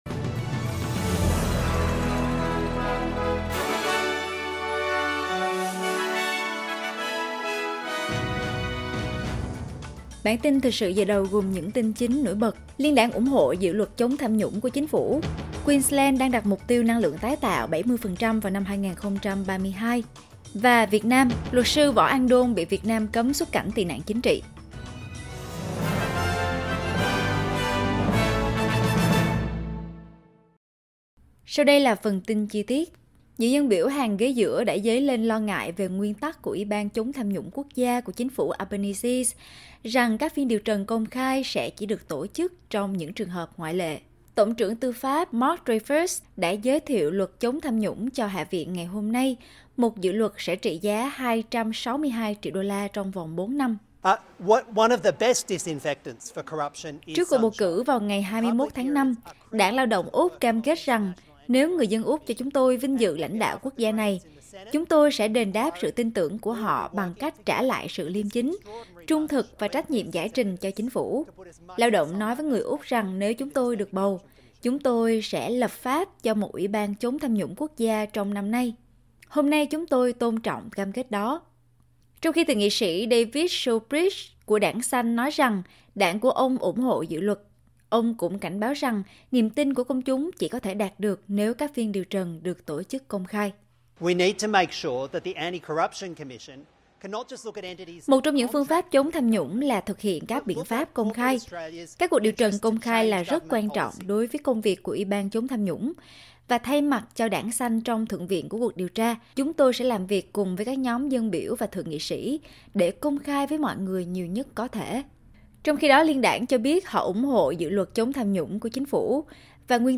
Bản tin thời sự 28/9/2022